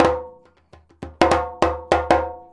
100DJEMB05.wav